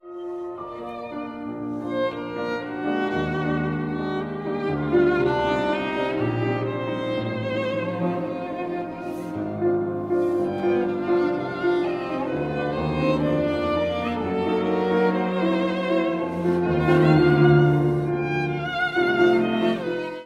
mp3Andrée, Elfrida, Piano Trio No.2, mvt. I Allegro agitato, mm. 44-52